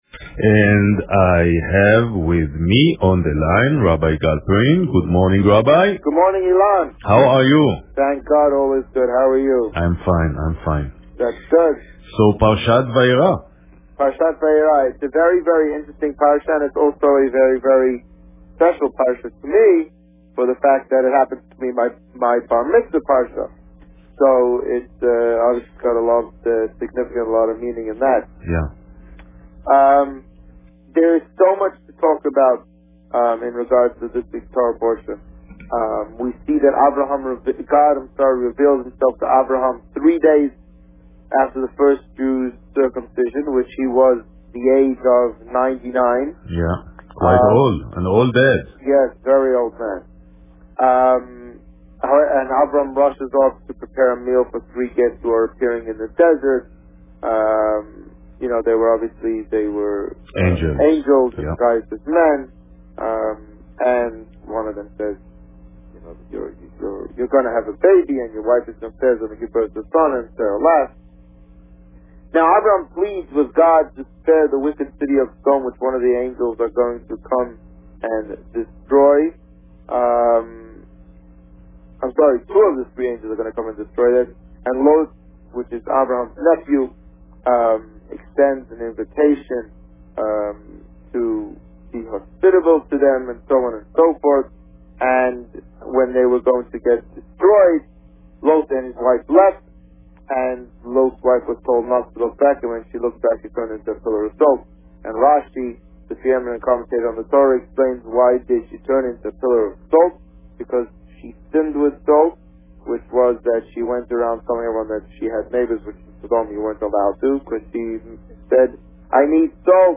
The Rabbi on Radio
Parsha Vayeira and Preparation for Chanukah Published: 01 November 2012 | Written by Administrator This week, the Rabbi spoke about Parsha Vayeira and preparations for the Chanukah community menorah. Listen to the interview here .